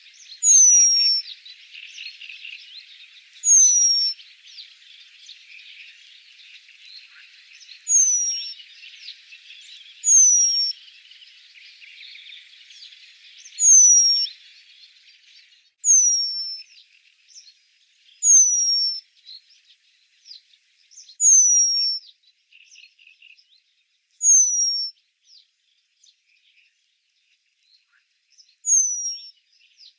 remiz-penduline.mp3